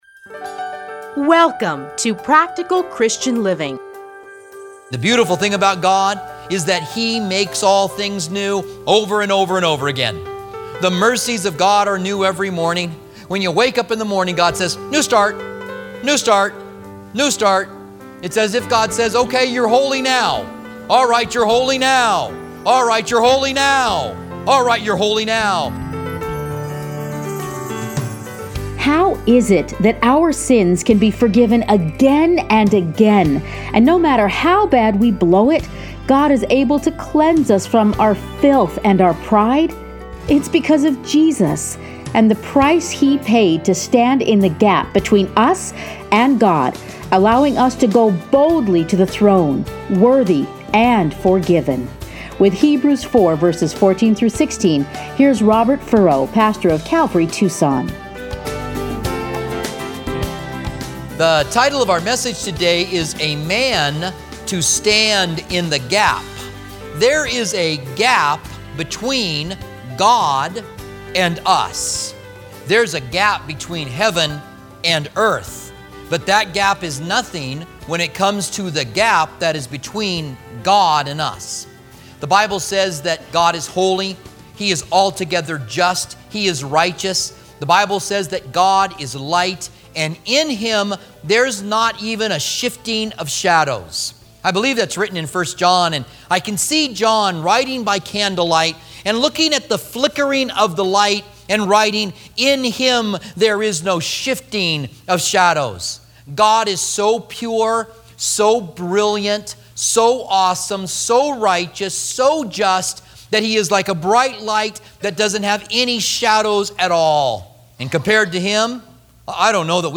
Listen here to a teaching from Hebrews.